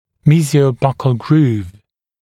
[ˌmiːzɪəu’bʌkl gruːv][ˌми:зиоу’бакл гру:в]межбугорковая фиссура